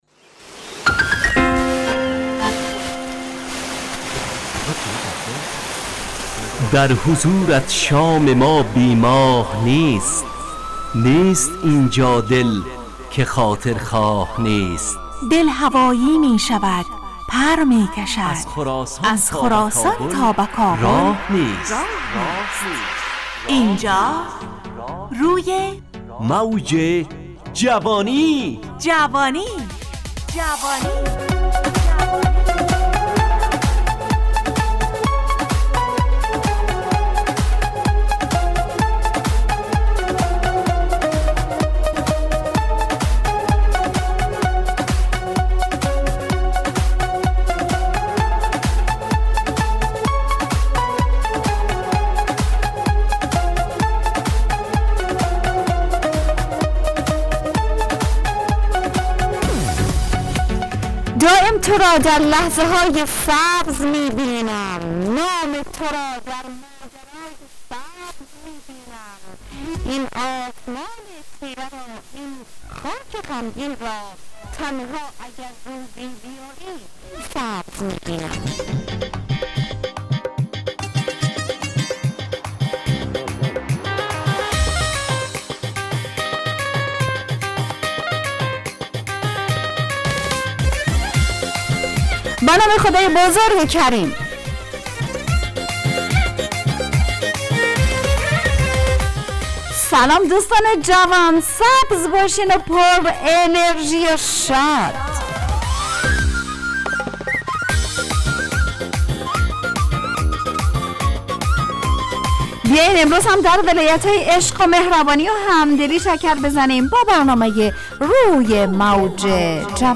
همراه با ترانه و موسیقی مدت برنامه 70 دقیقه . بحث محوری این هفته (مسجد) تهیه کننده